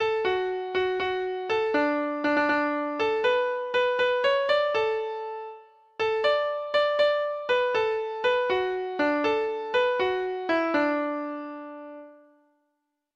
Traditional Trad. The Meeks Murder (3) Treble Clef Instrument version
Folk Songs from 'Digital Tradition' Letter T The Meeks Murder (3)